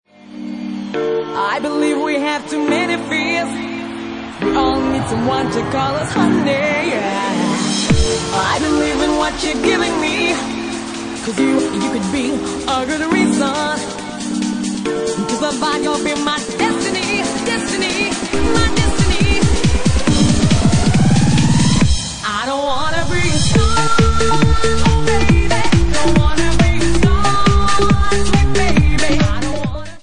Bassline House at 139 bpm